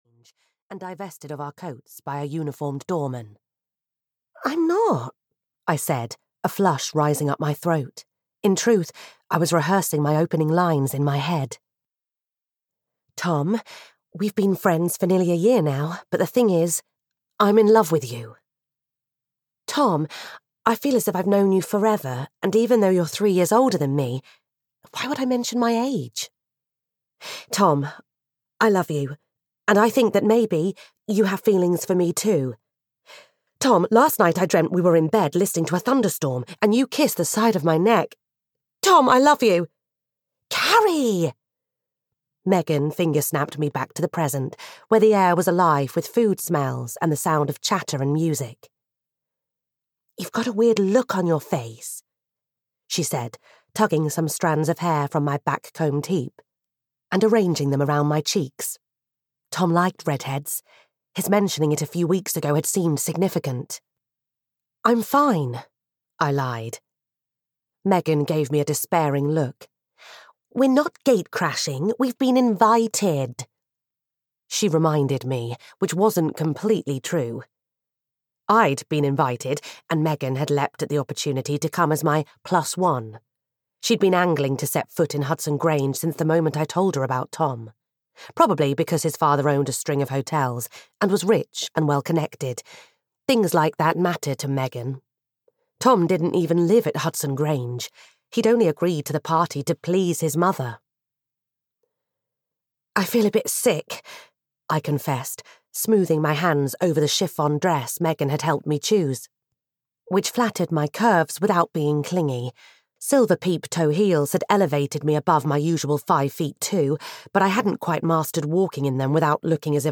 The Beachside Flower Stall (EN) audiokniha
Ukázka z knihy